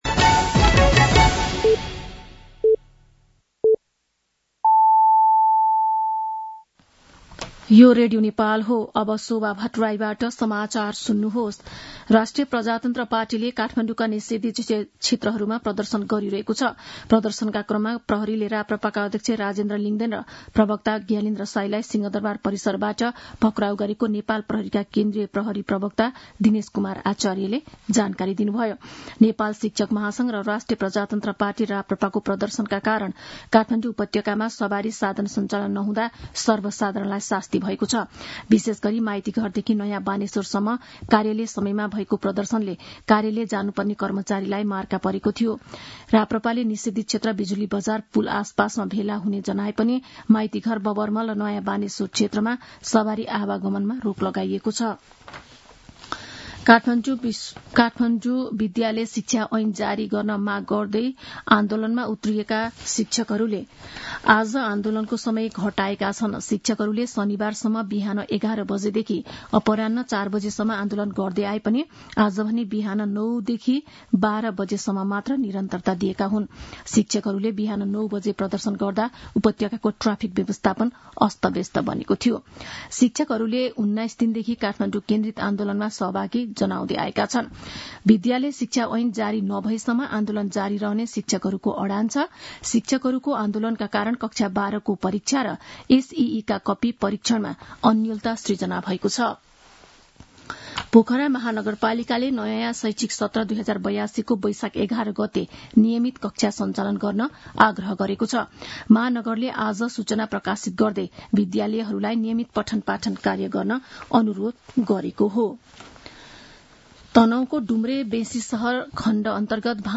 दिउँसो ४ बजेको नेपाली समाचार : ७ वैशाख , २०८२
4-pm-Nepali-News-01-07.mp3